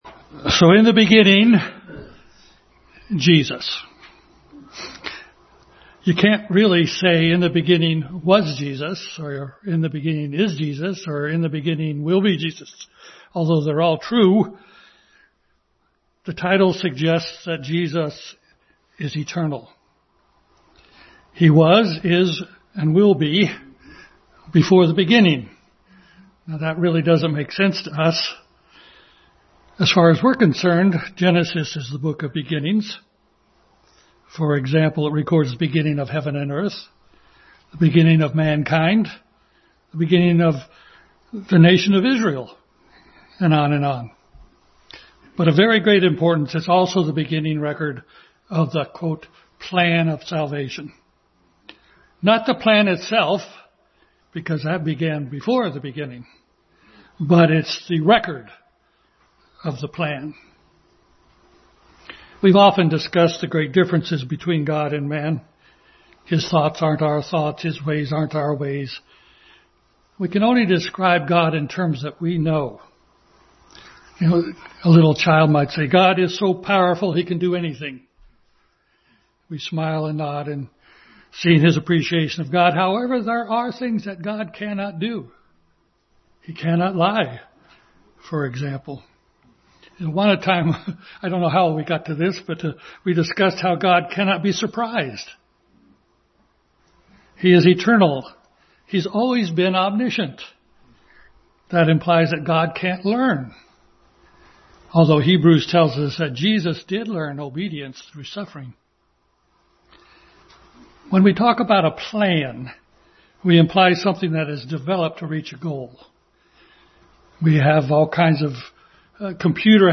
Jesus Passage: Genesis 1, Exodus 12, Isaiah 53, Genesis 22, 18:14, John 3:16, Psalm 22, 23, 24 Service Type: Family Bible Hour